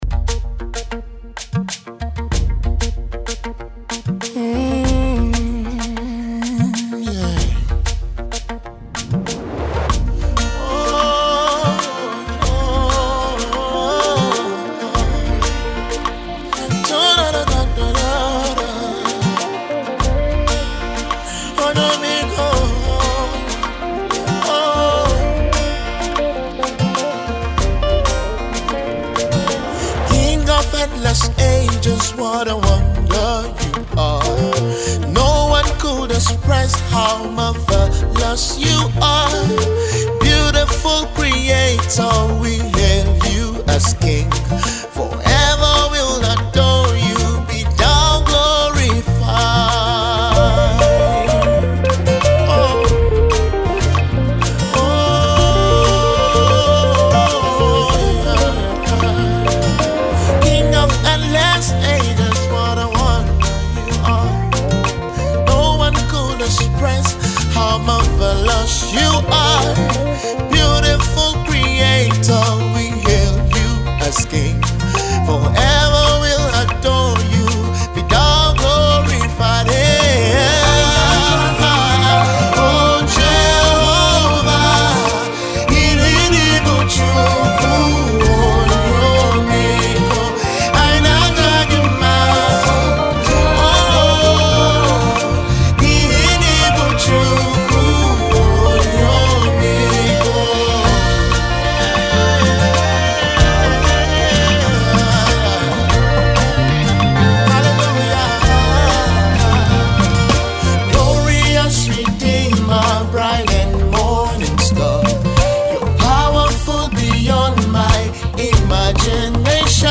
soul lifting song